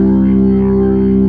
55o-org02-C1.wav